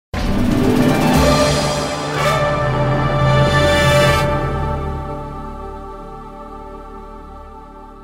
На этой странице собраны звуки викторин — от классических сигналов правильного ответа до зажигательных фанфар.